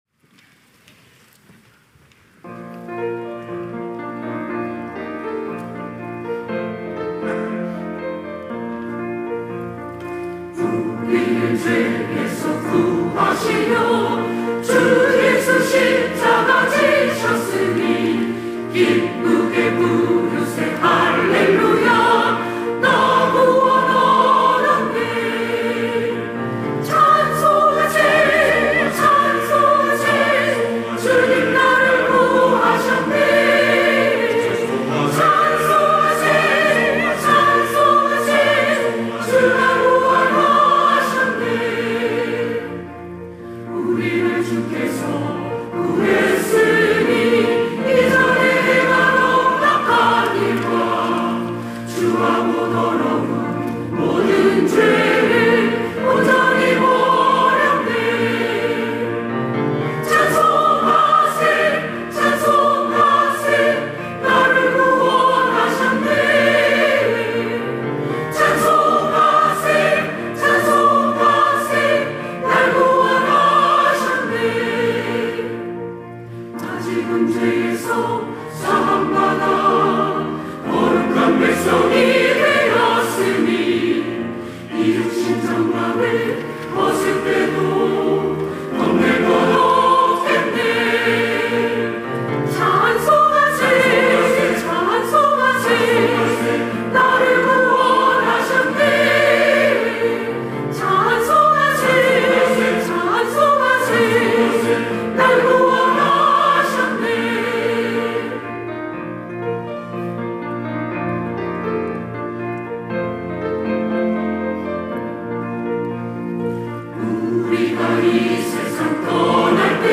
시온(주일1부) - 우리를 죄에서 구하시려
찬양대 시온